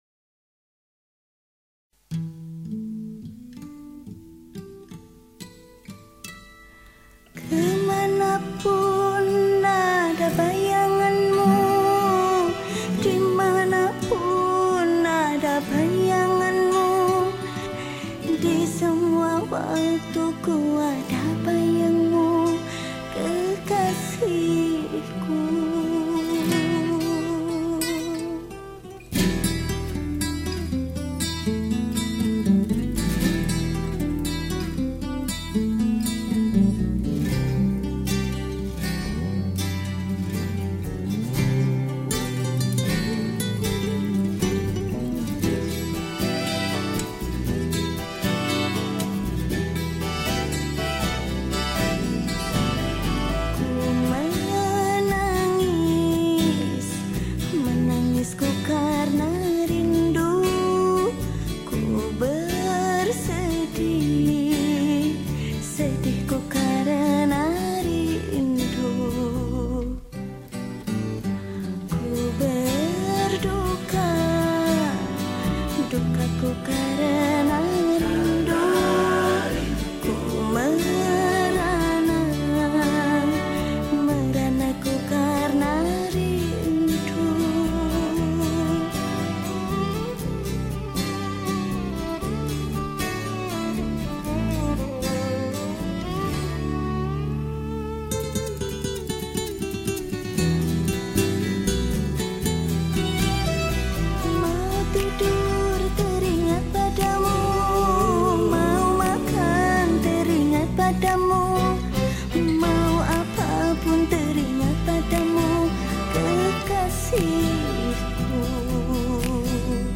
Professi                                    : Penyanyi
Genre Musik                            : Dangdut Original